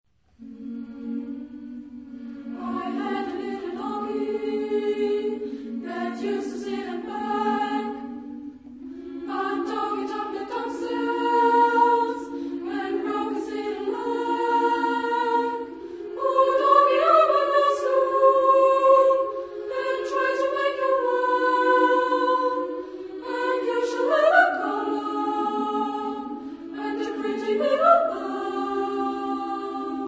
Genre-Style-Forme : Cycle ; Blues ; Pièce chorale ; Profane
Type de choeur : SAAAA  (5 voix égales de femmes )
Tonalité : libre